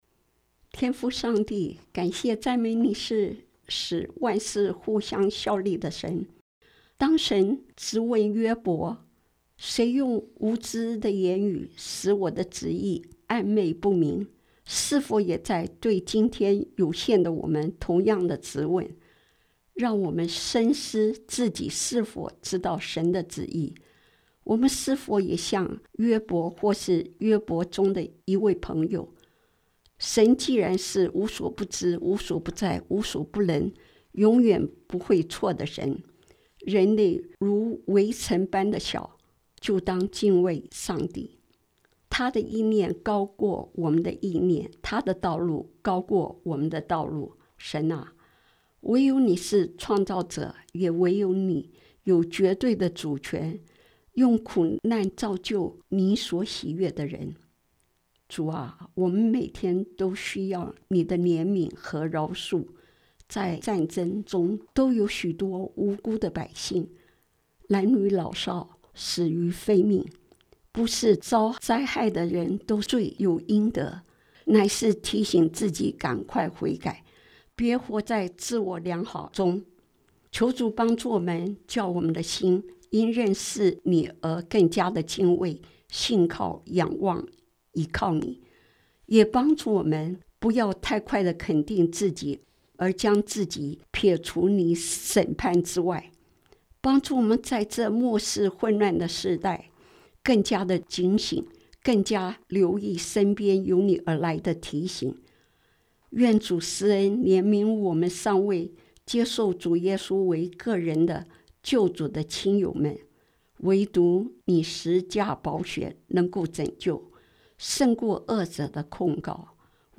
祷告词